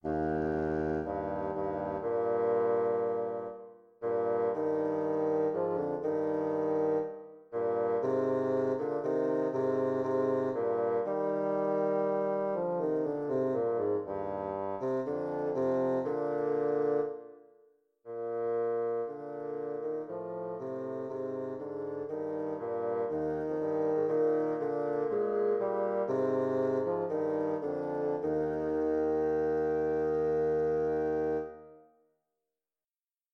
bassoon music , double reed